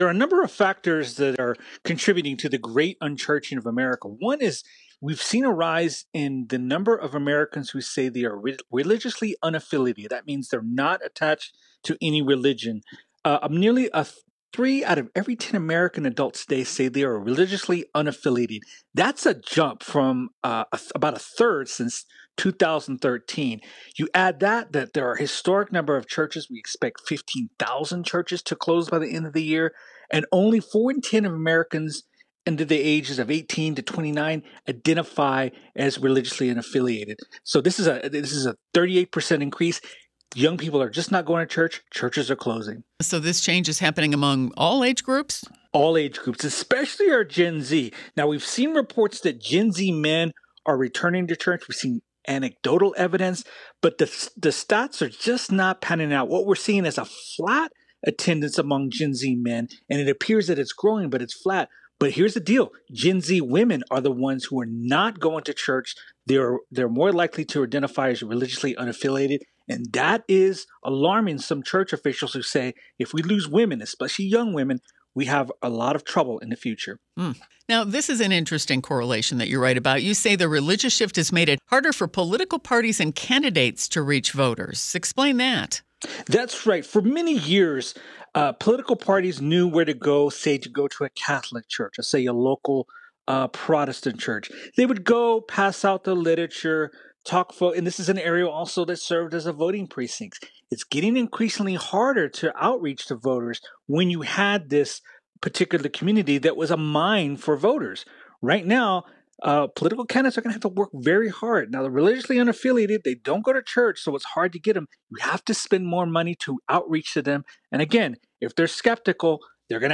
In an interview with WTOP